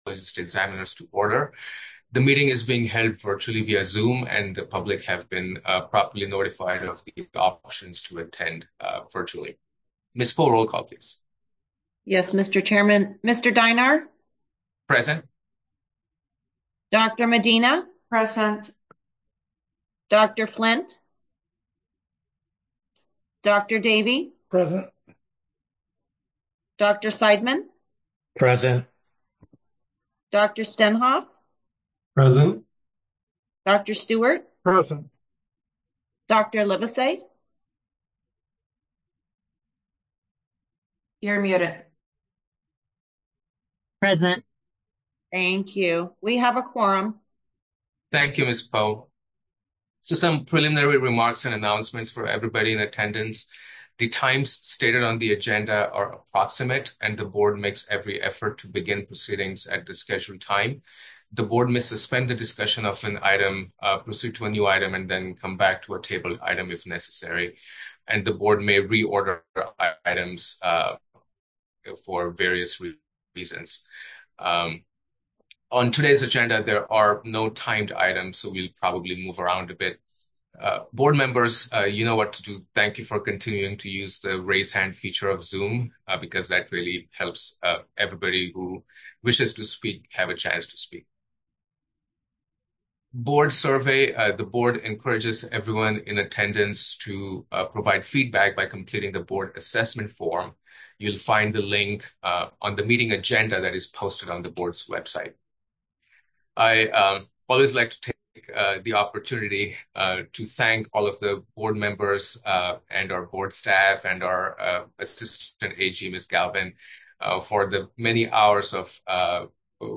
Board Meeting | Board of Psychologist Examiners
Members will participate via Zoom